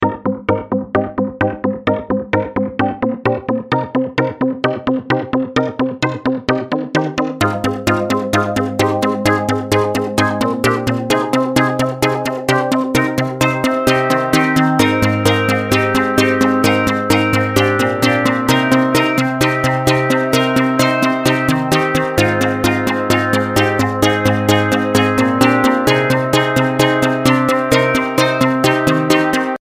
spectrum-pluck-remake_14281.mp3